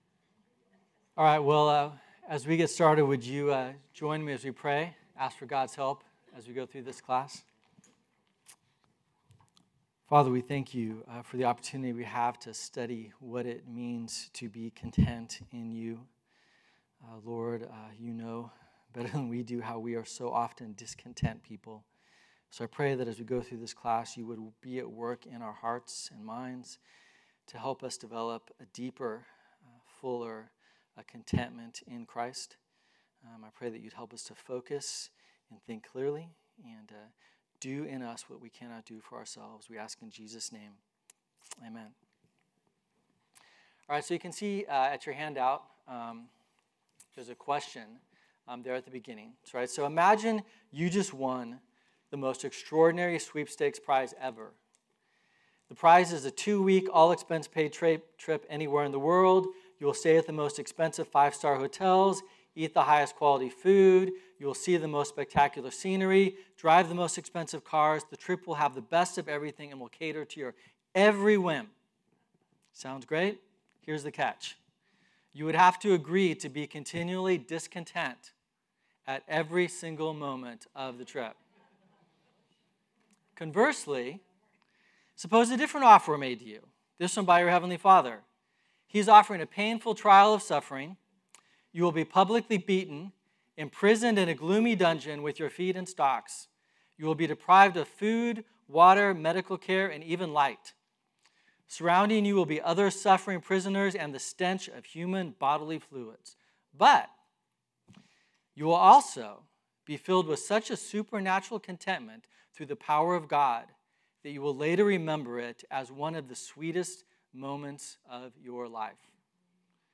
Listen to Message
Type: Sunday School